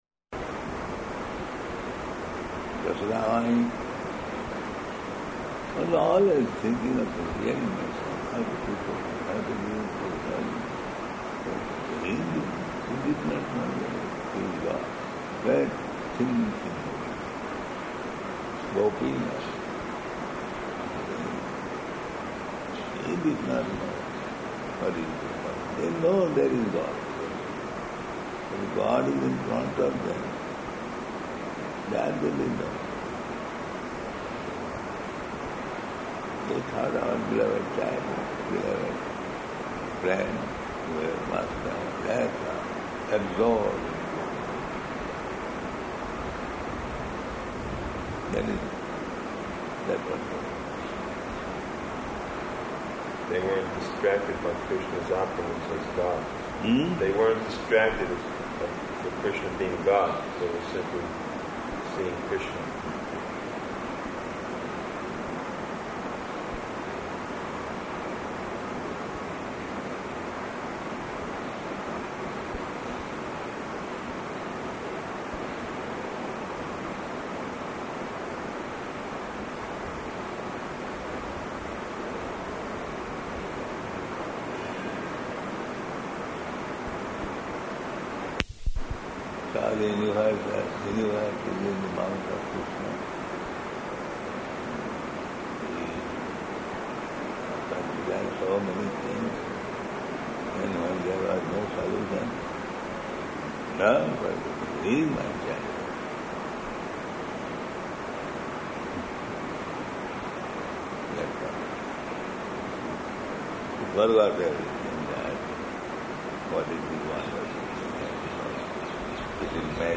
Room Conversation
Room Conversation --:-- --:-- Type: Conversation Dated: July 1st 1977 Location: Vṛndāvana Audio file: 770701R3.VRN.mp3 Prabhupāda: Yaśodāmāyi was always thinking of Him, "Here is my son.